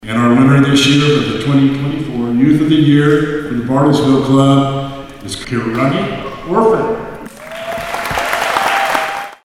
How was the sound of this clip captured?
Hundreds of supporters gathered Friday morning at the Boys and Girls Club in Bartlesville to honor several club members, but one special girl took home an incredible honor.